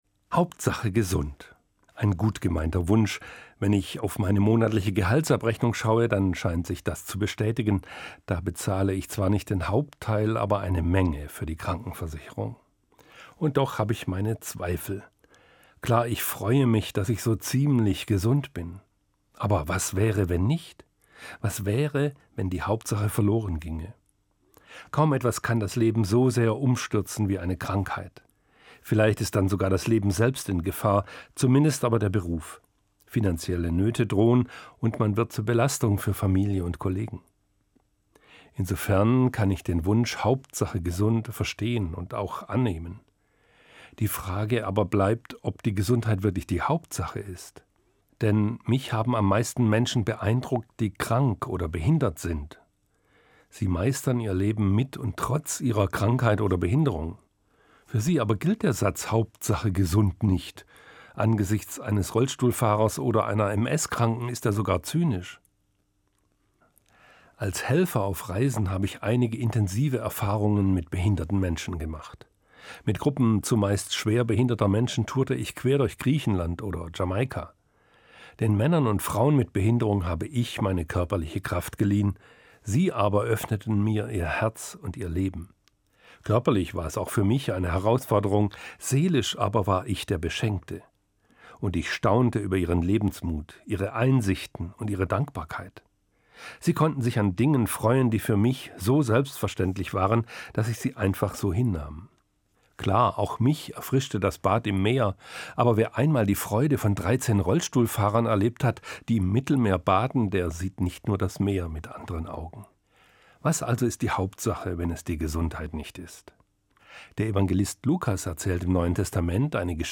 Evangelischer Pfarrer, Frankfurt